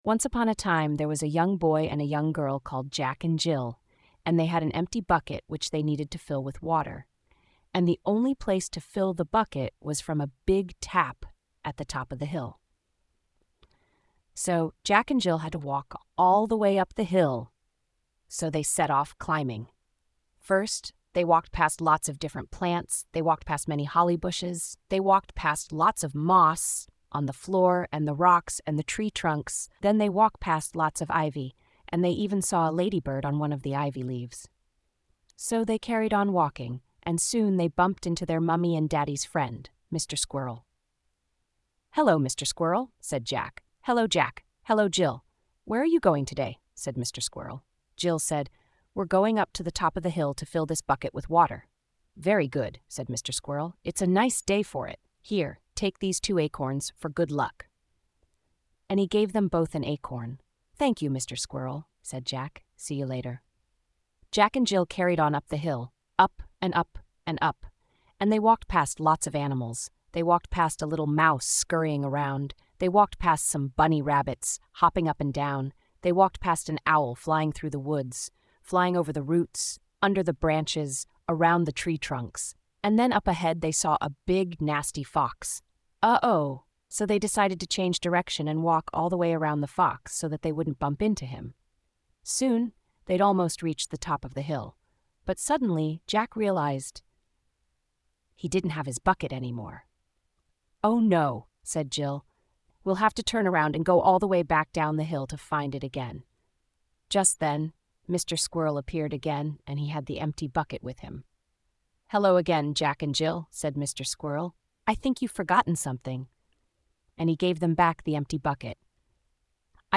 Bedtime Stories